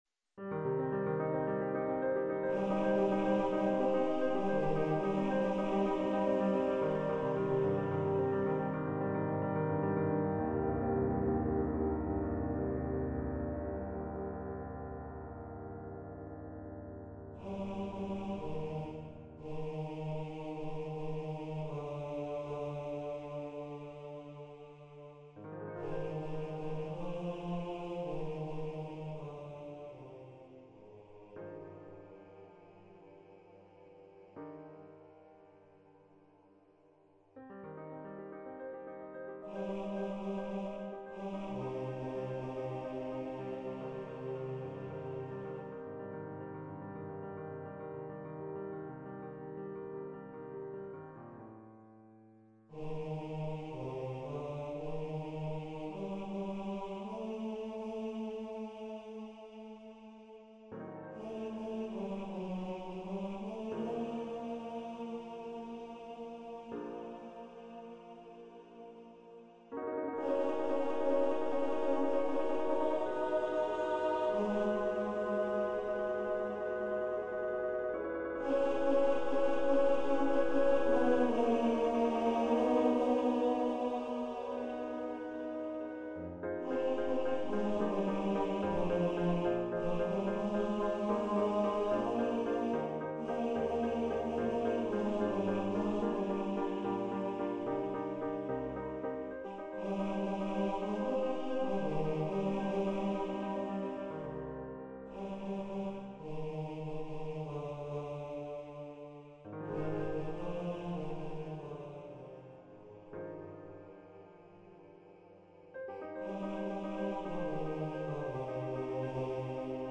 Baritone Voice and Piano
Composer's Demo